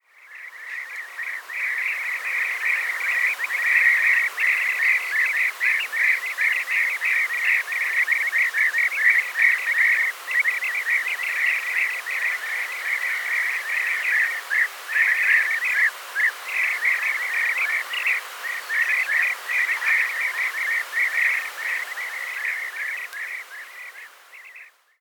Звуки золотистой щурки
Птичья стая в небесном танце